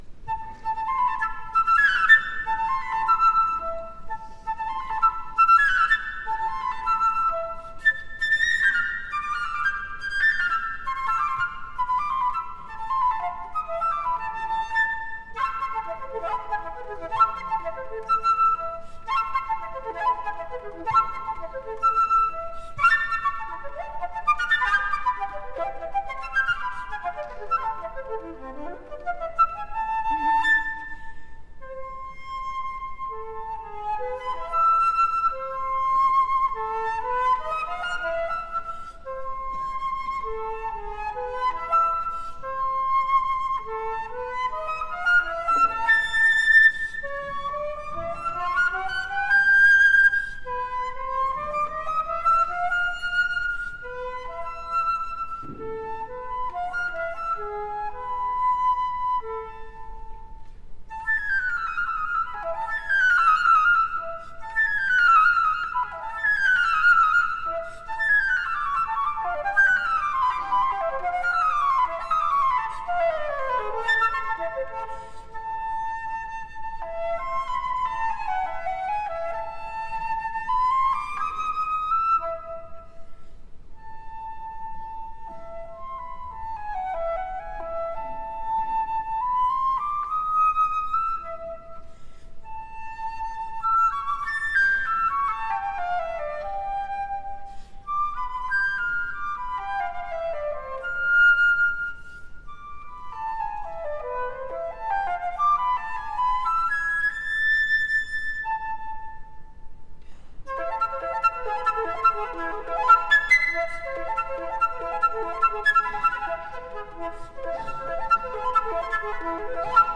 MOZART, flute solo@@.mp3